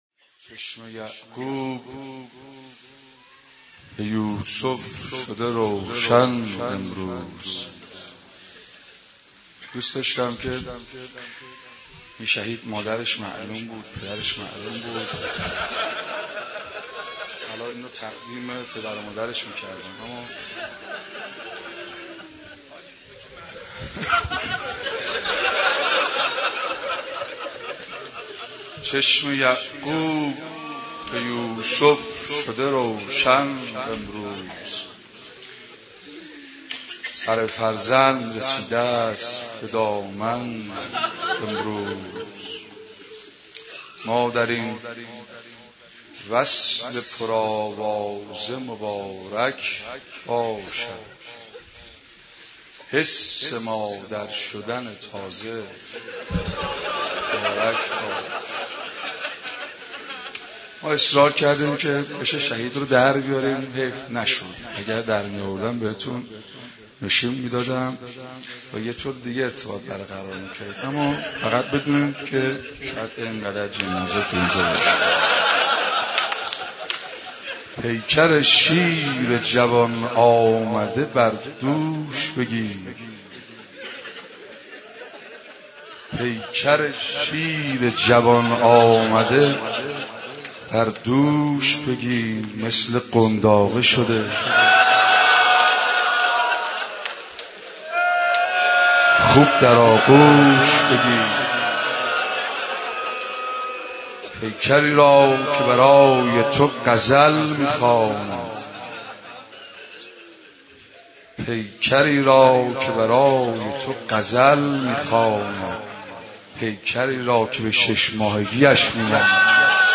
صوت روضه شهید گمنام